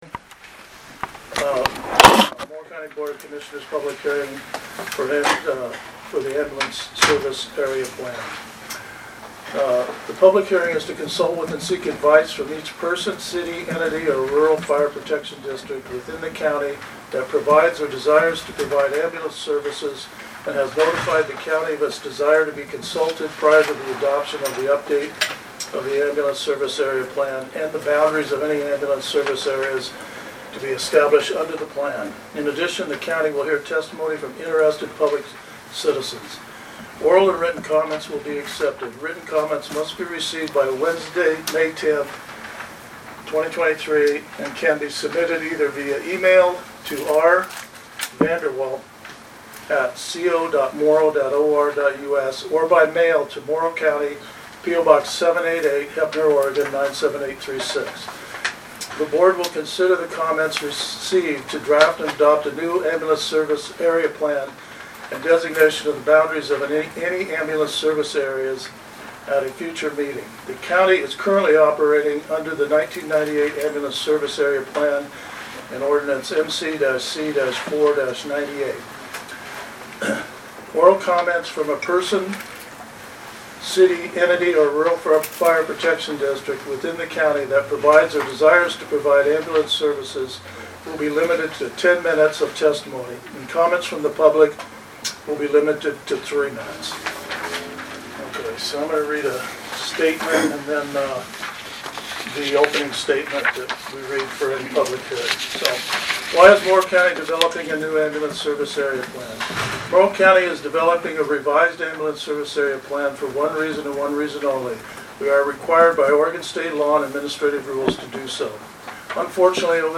Board of Commissioners Public Hearing - Irrigon | Morrow County Oregon
5-10-23_boc_asa_public_hearing_irrigon_6_pm.mp3